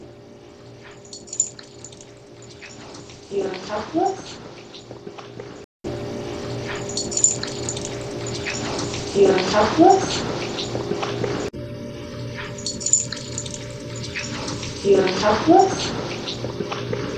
Basement
Get Out - I was sitting by the freezer when this one occurred.